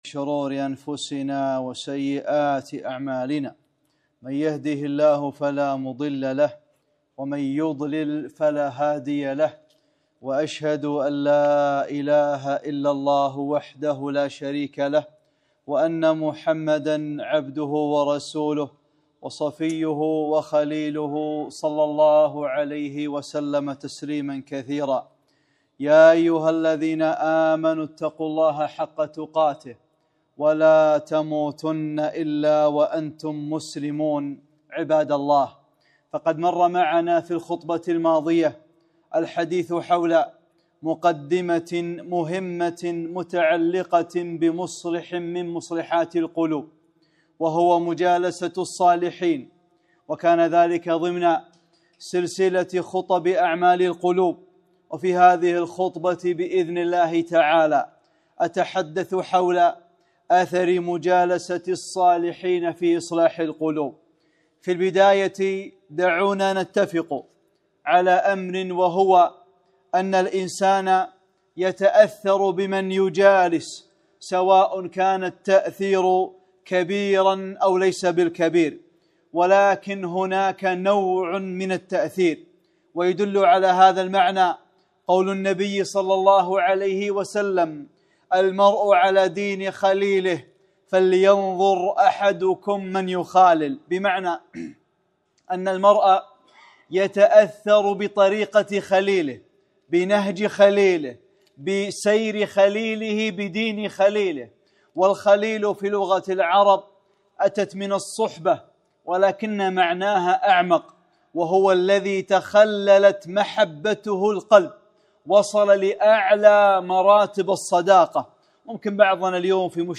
خطبة - (5) مجالسة الصالحين | أعمال القلوب - دروس الكويت